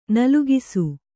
♪ nalugisu